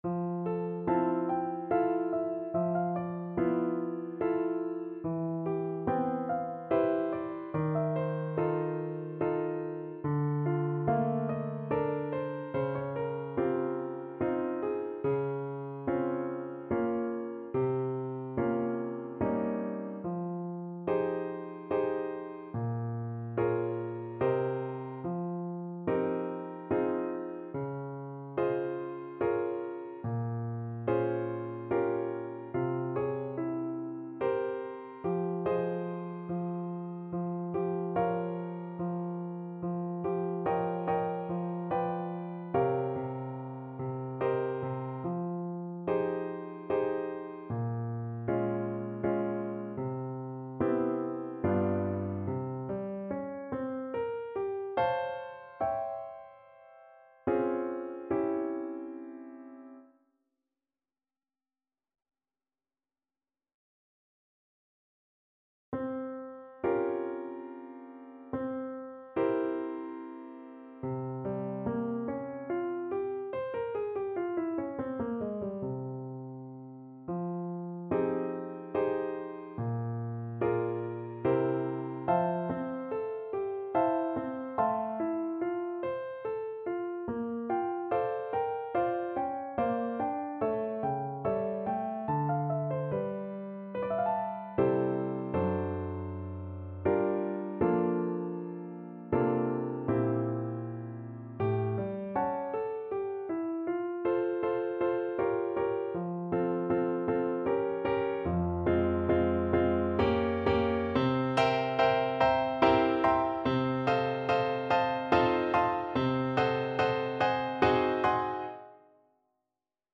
Play (or use space bar on your keyboard) Pause Music Playalong - Piano Accompaniment Playalong Band Accompaniment not yet available transpose reset tempo print settings full screen
F minor (Sounding Pitch) G minor (Clarinet in Bb) (View more F minor Music for Clarinet )
Andante =72